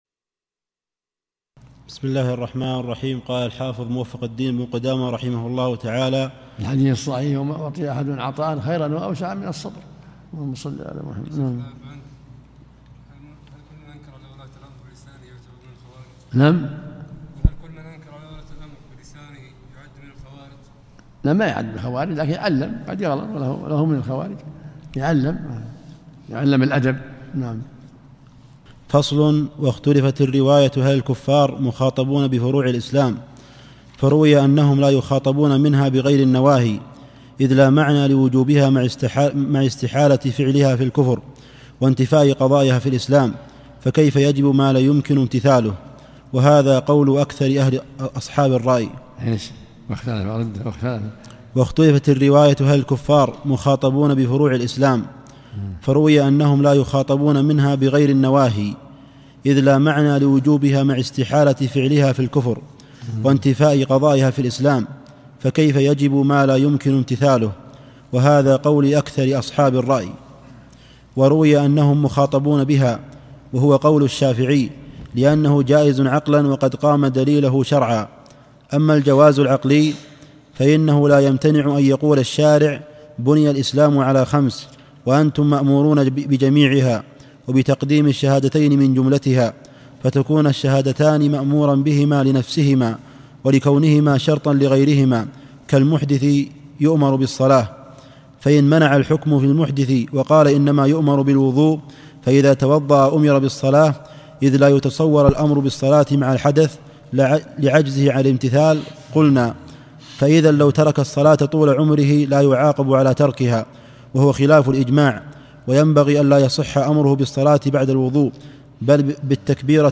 الدرس الخامس